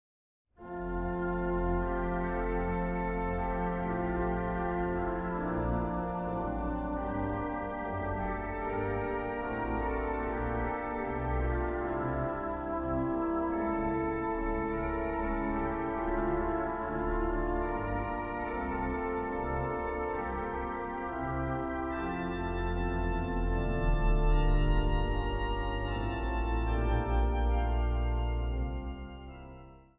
orgel.
Zang | Samenzang